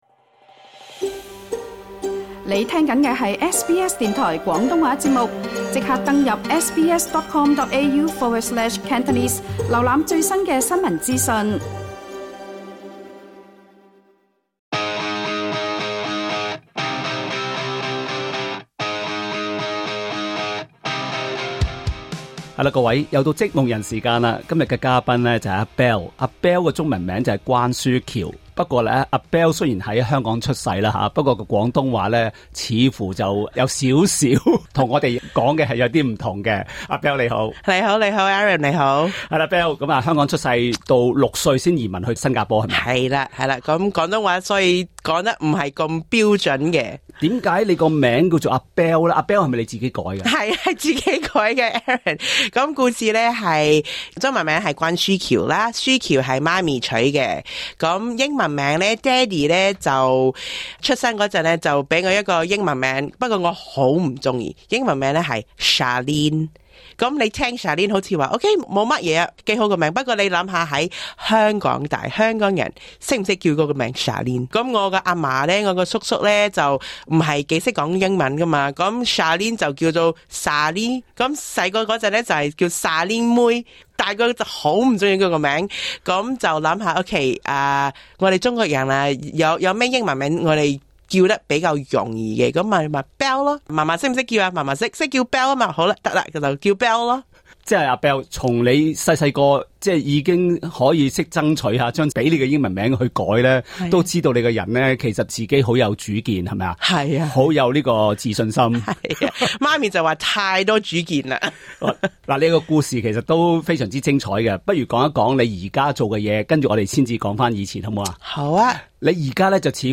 SBS 電台接受訪問